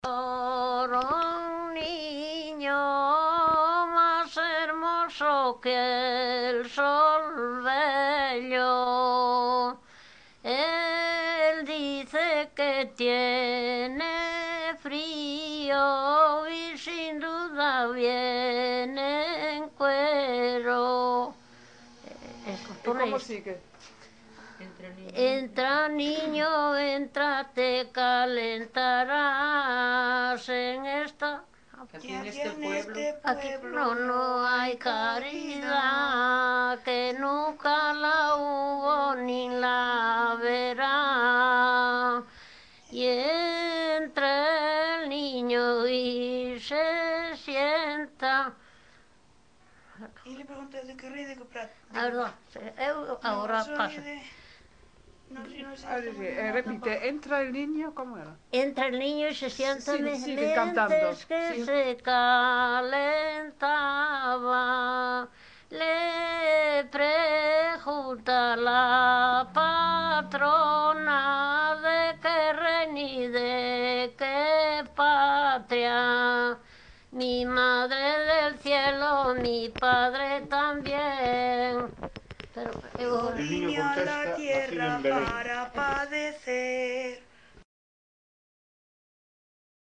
Tipo de rexistro: Musical
Áreas de coñecemento: LITERATURA E DITOS POPULARES > Cantos narrativos
FESTAS > Festas universais do ciclo anual > Nadal
Soporte orixinal: Casete
Datos musicais Refrán
Instrumentación: Voz
Instrumentos: Voces femininas